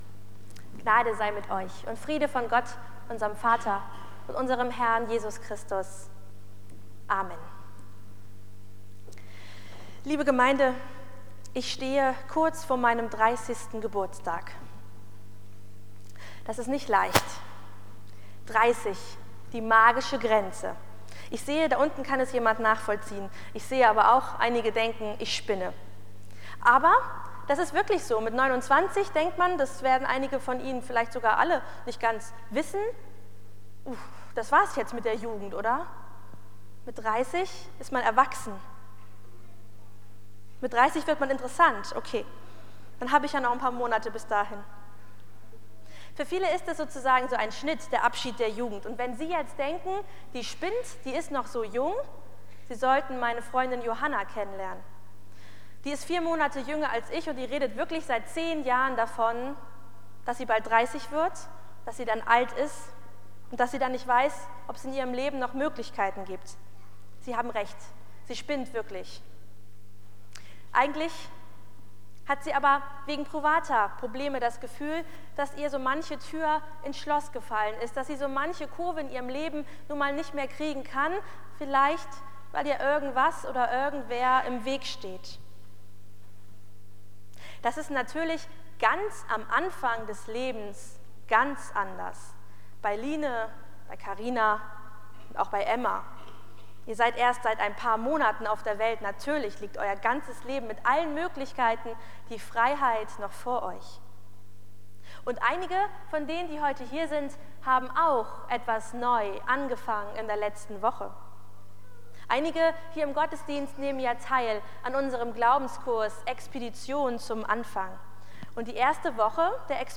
Predigt2.mp3